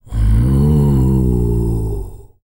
TUVANGROAN03.wav